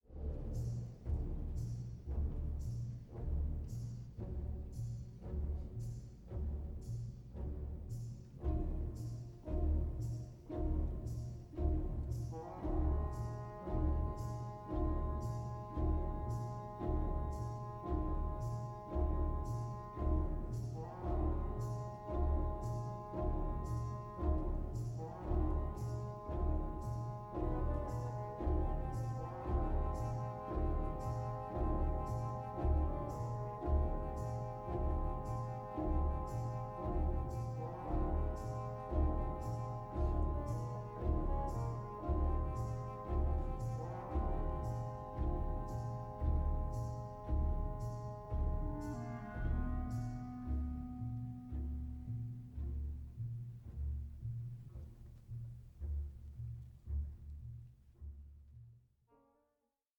barbaric and savage music